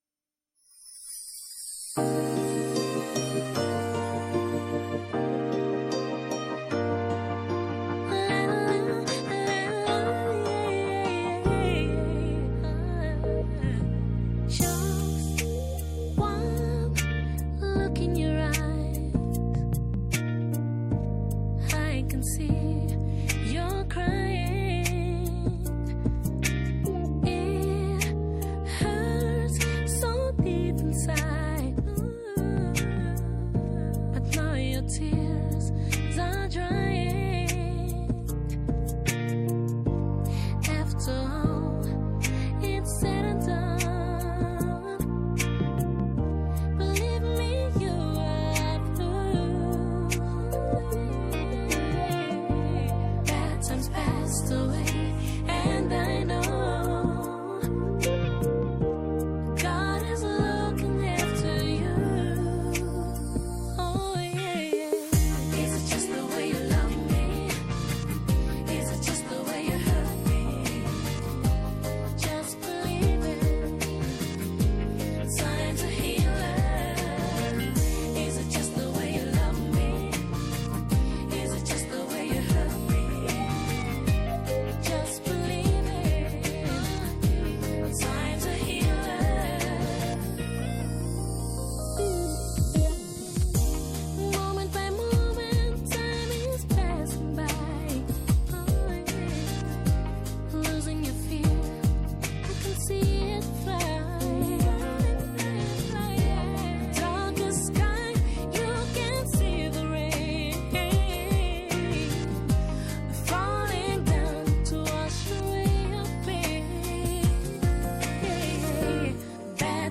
Christian, pop, R&B and dance musical group